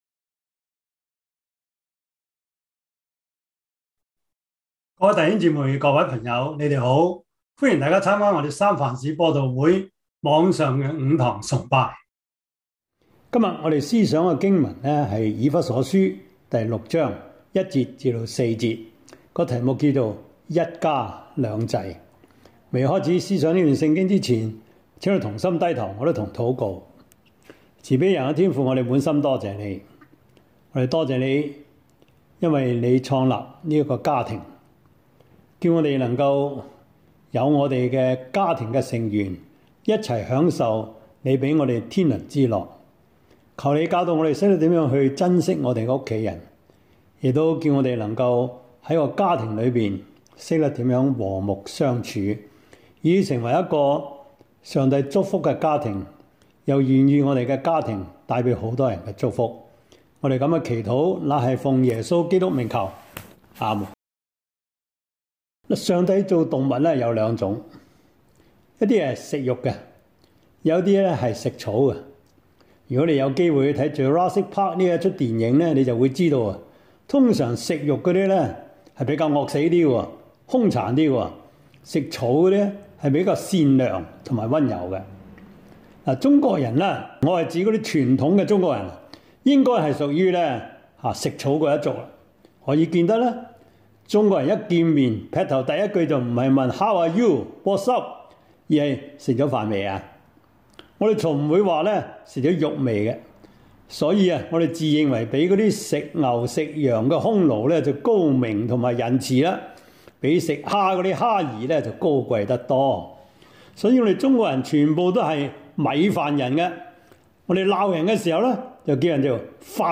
Service Type: 主日崇拜
Topics: 主日證道 « 晨星在你們心裡出現 如何讀聖經 – 第二課 »